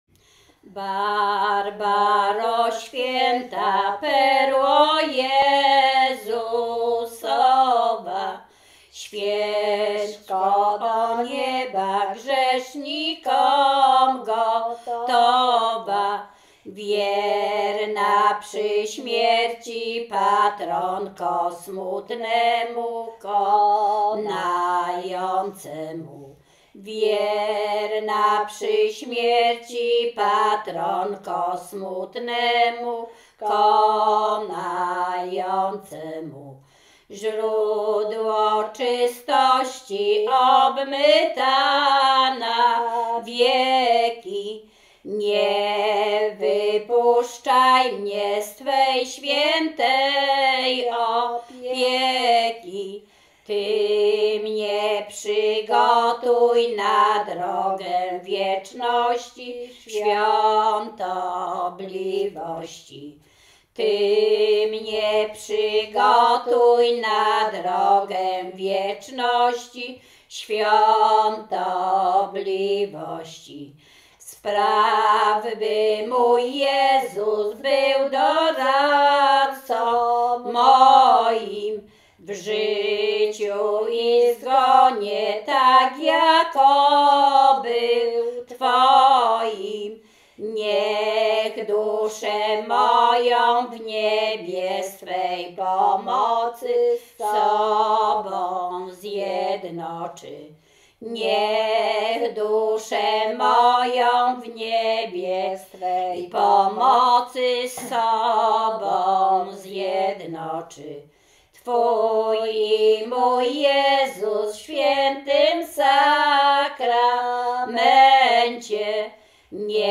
Śpiewaczki z Czerchowa
Łęczyckie
Pogrzebowa